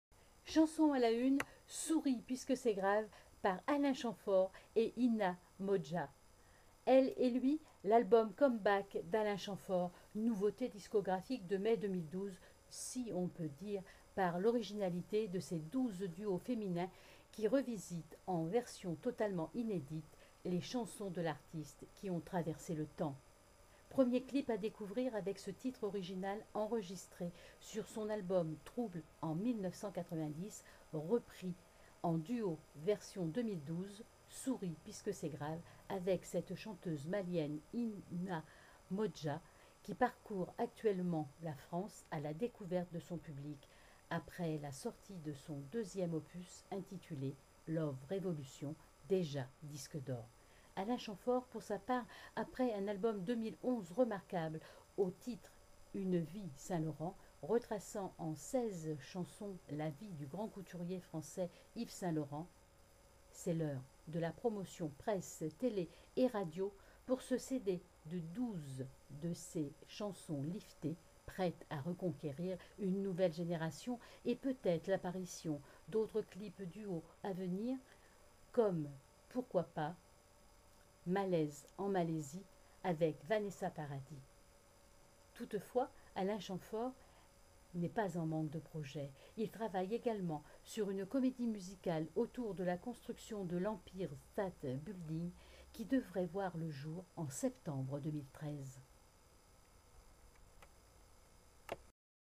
repris en duo version 2012